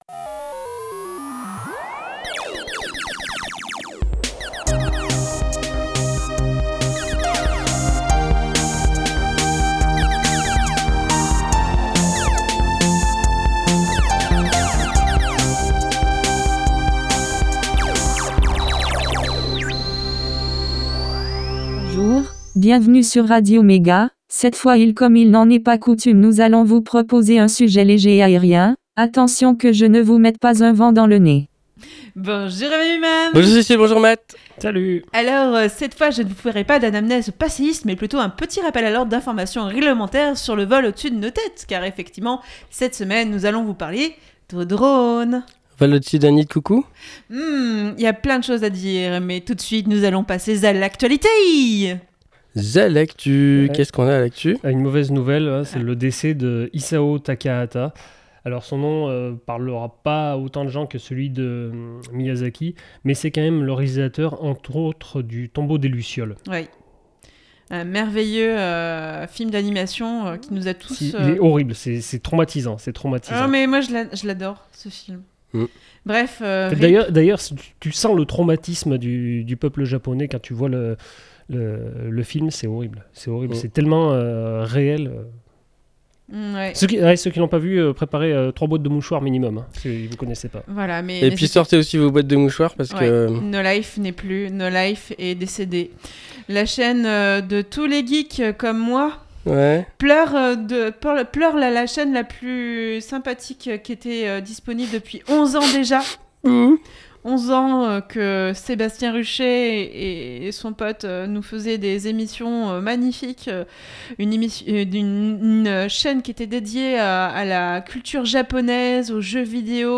Ca me fait tout drône De l'actu, une pause chiptune, un sujet, l'agenda, et astrologeek !